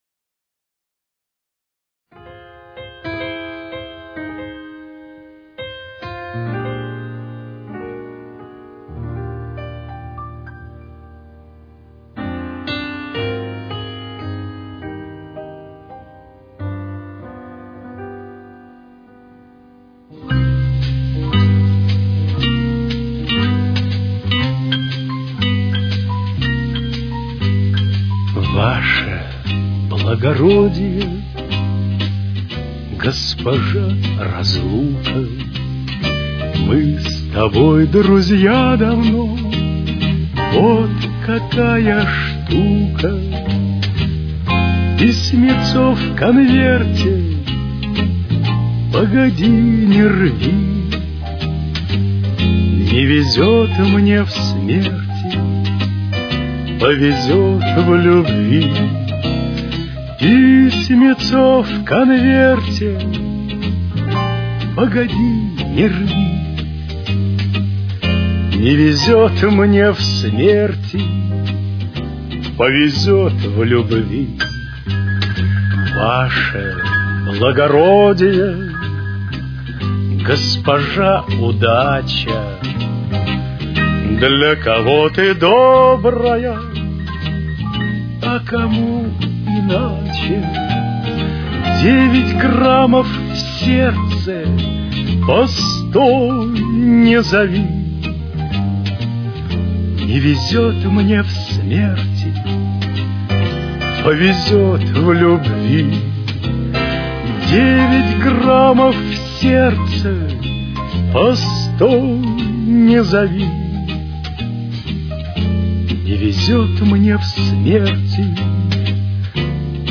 с очень низким качеством (16 – 32 кБит/с)
Темп: 63.